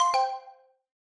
newNotif03.ogg